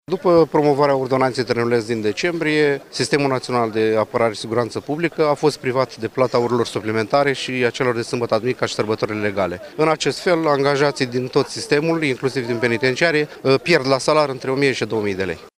Aproximativ 200 de polițiști de la Penitenciarul Vaslui au protestat, de dimineață, în fața unității, nemulțumiți de prevederile Ordonanței „trenuleț”, prin care li se reduc semnificativ veniturile.